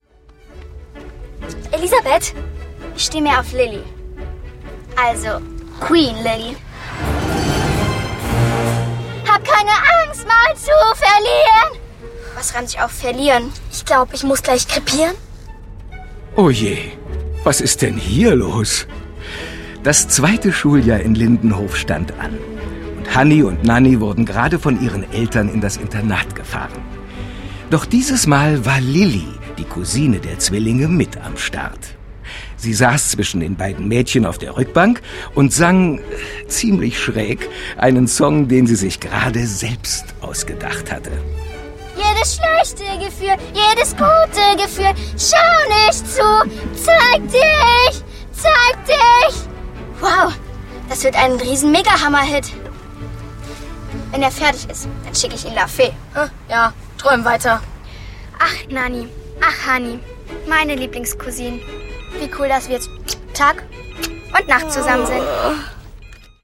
Hanni und Nanni - Das Original-Hörspiel zum Kinofilm 2